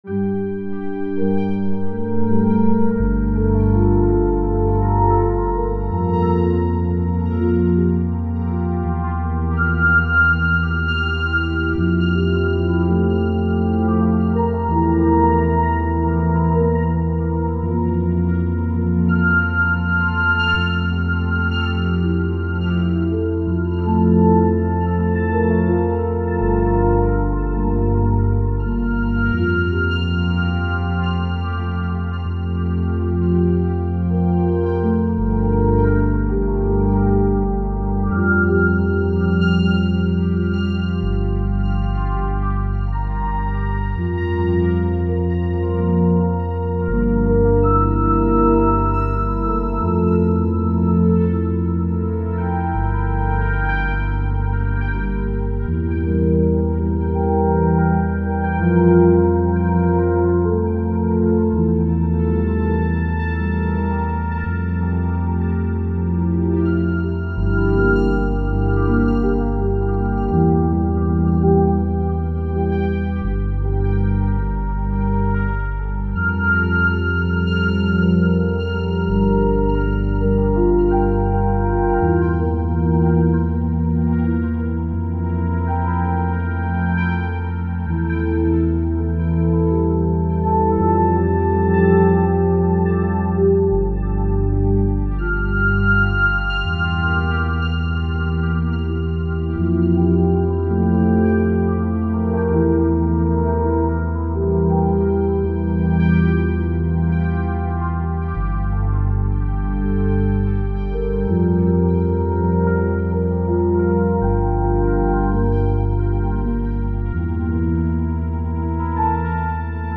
Brumenn est un duo piano électrique/flute alors que nijal est un morceau ambient/New age.
Henon ( 1.8 - 0.5) Sol (G) Dorien 82